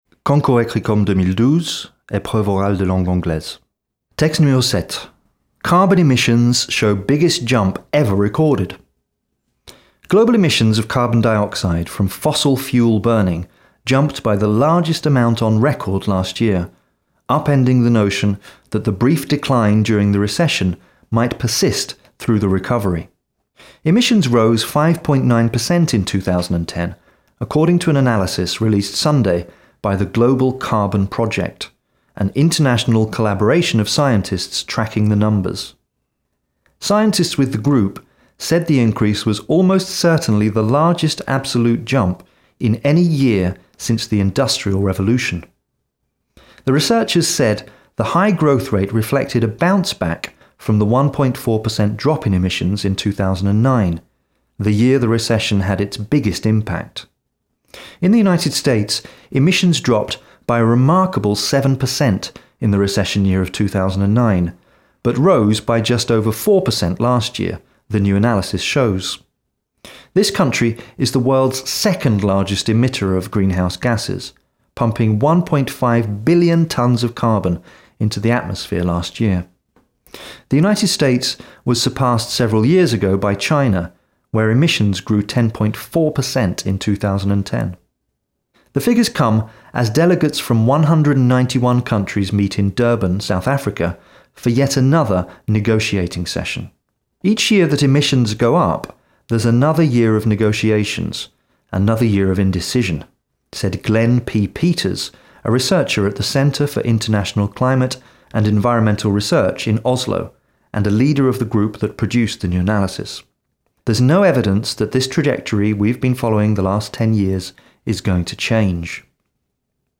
Ce sont des extraits d'articles de presse, enregistrés par des voix anglo-saxonnes, et convertis ici au format mp3.